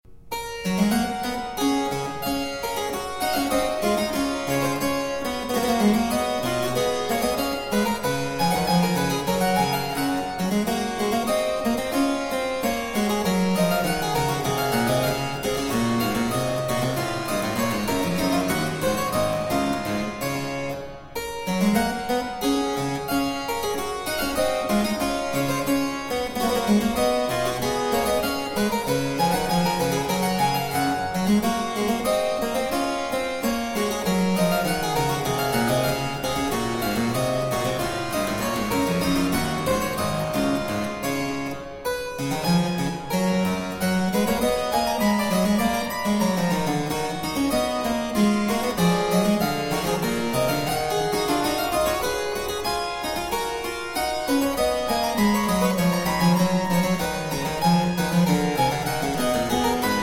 solo harpsichord work
Harpsichord